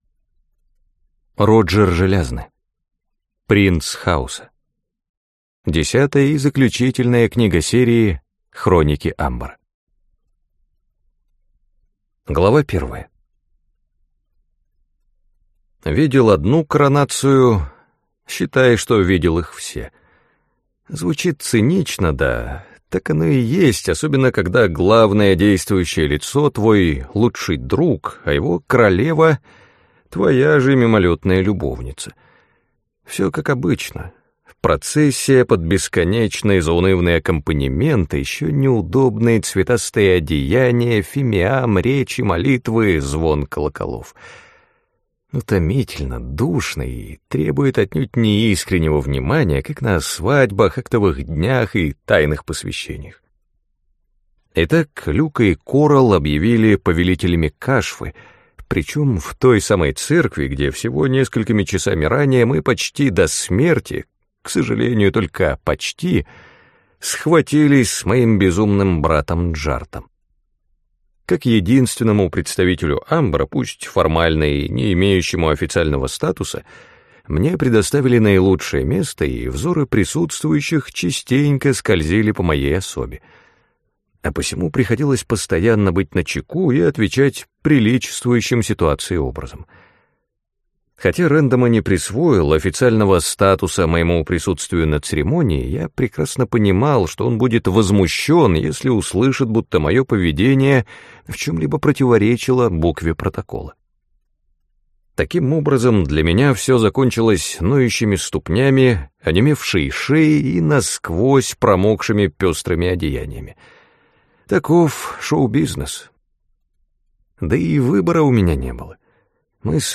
Аудиокнига Принц Хаоса | Библиотека аудиокниг